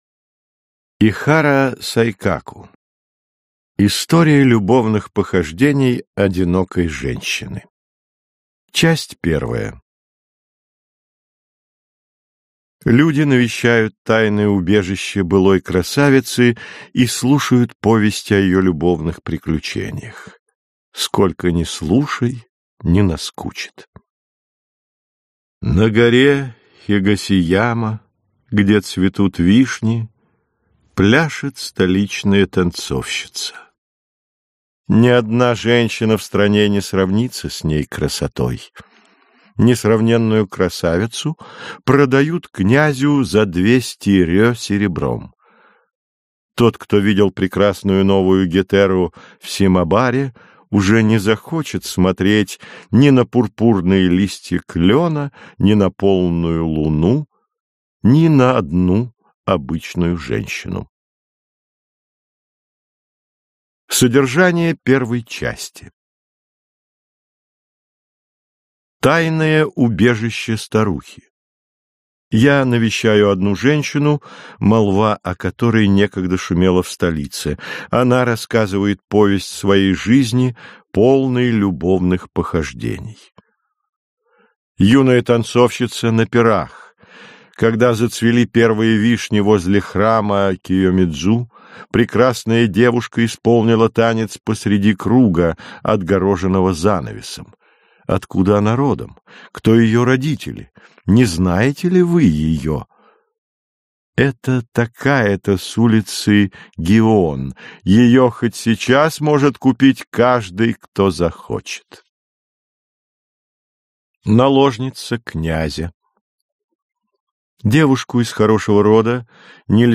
Аудиокнига История любовных похождений одинокой женщины | Библиотека аудиокниг
Прослушать и бесплатно скачать фрагмент аудиокниги